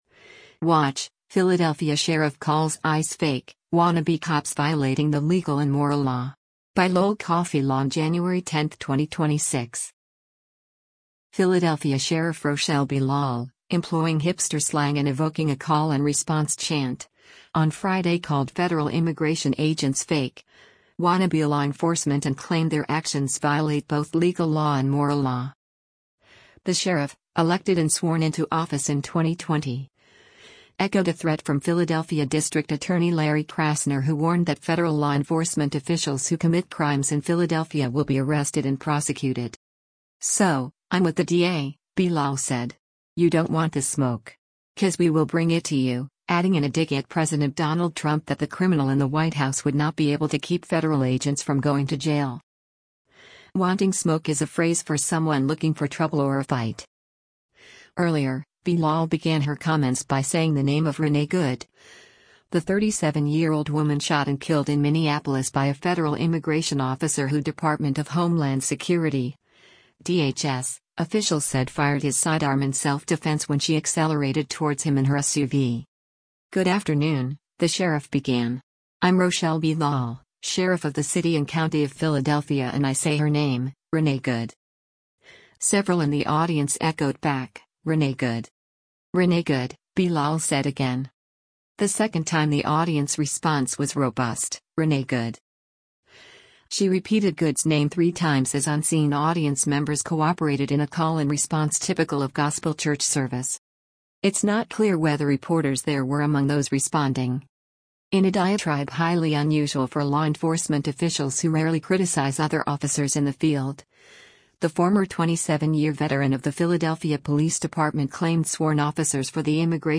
Philadelphia Sheriff Rochelle Bilal speaks during a news conference in Philadelphia, Thurs
Philadelphia Sheriff Rochelle Bilal — employing hipster slang and evoking a call-and-response chant — on Friday called federal immigration agents “fake, wannabe” law enforcement and claimed their actions violate both “legal law” and “moral law.”